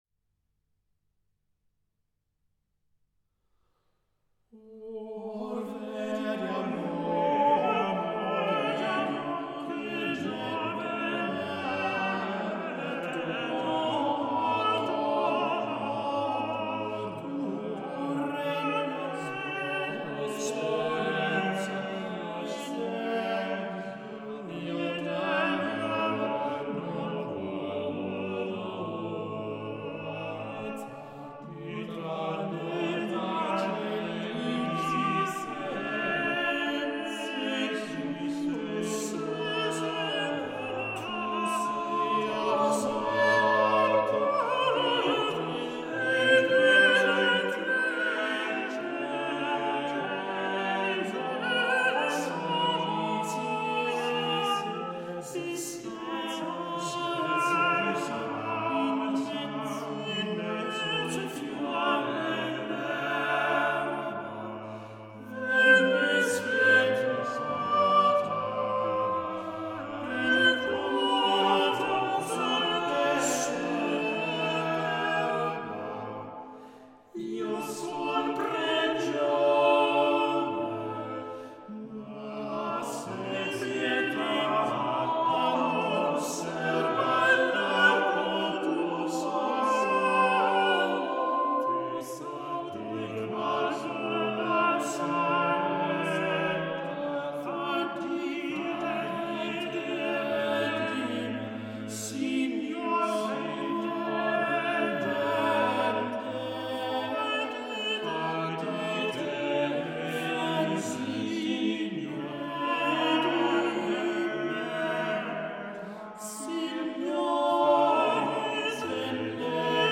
One of the world’s finest vocal chamber groups
at the St Gerold monastery in Austria